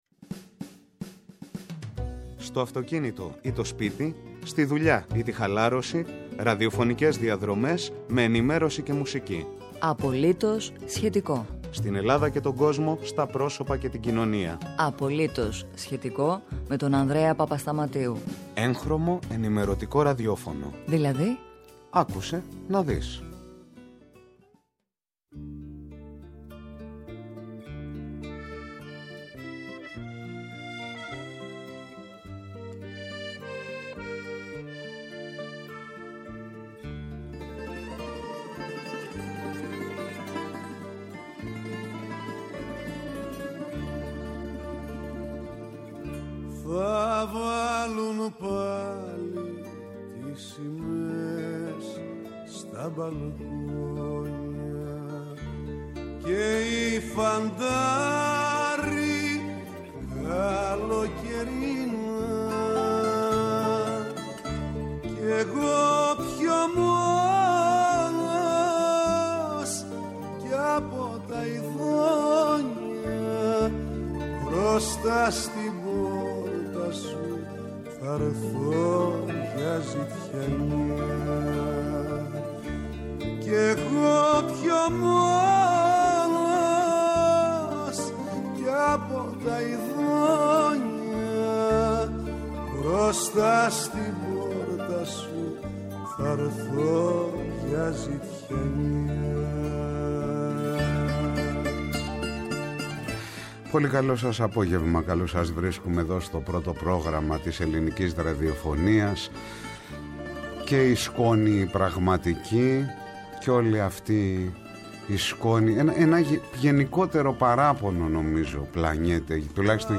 Καλεσμένος ο Συνταγματολόγος, Κώστας Χρυσόγονος.